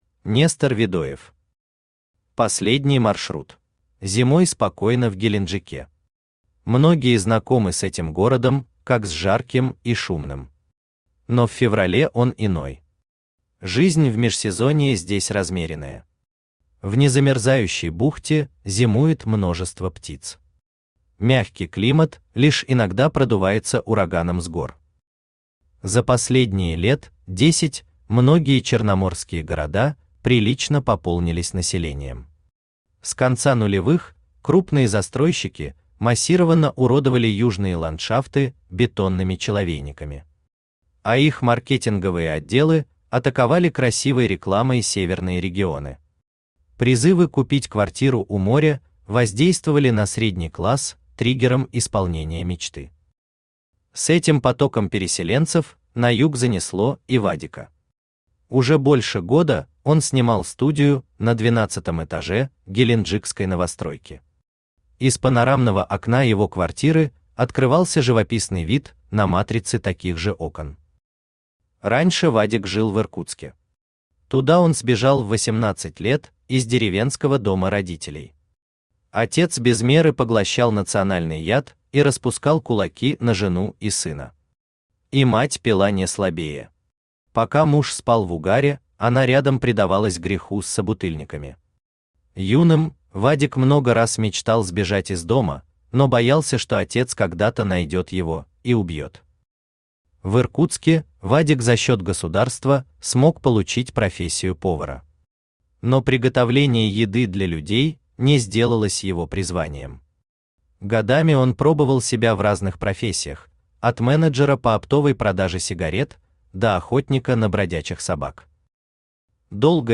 Aудиокнига Последний маршрут Автор Нестор Видоев Читает аудиокнигу Авточтец ЛитРес.